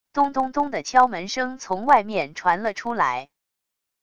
咚咚咚的敲门声从外面传了出来wav音频